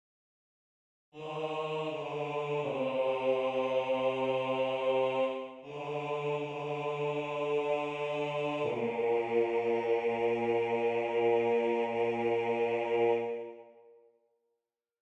Key written in: B♭ Major
How many parts: 4
Type: Barbershop